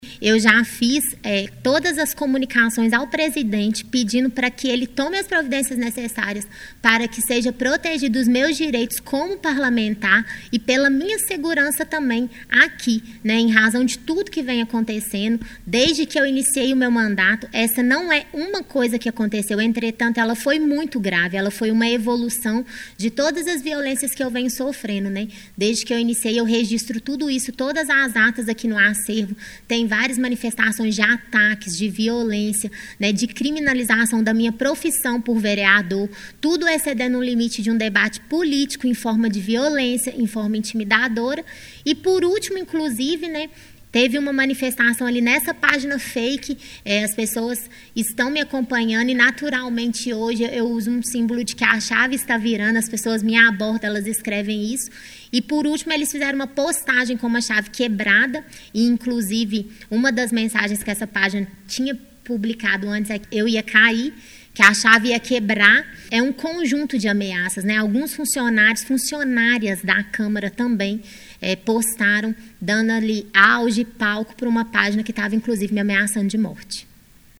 Ela convidou a imprensa para uma coletiva onde falou sobre o que vem sofrendo nos últimos meses.